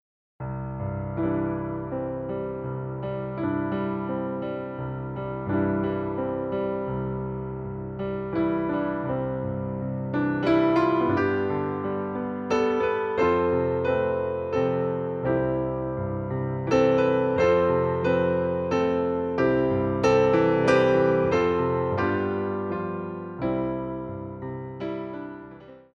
Rond de Jambe